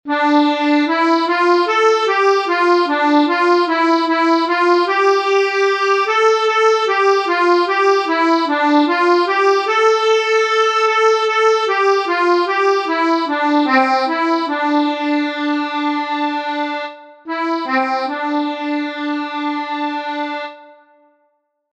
Exercise 3: 6/8 time signature.
Melodic reading practice exercise 3
melodic_reading_3.mp3